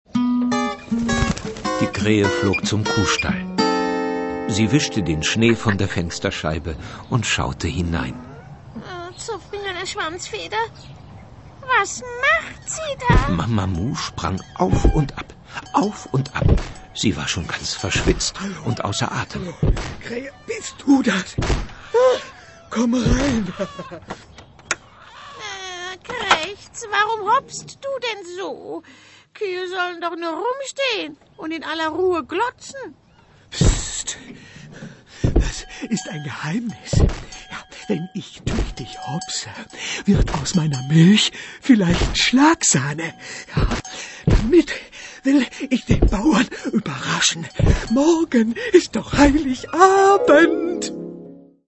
Liebevolles Hörspiel mit der neugierigsten Kuh der Welt - ein Muss für jedes Kinderzimmer!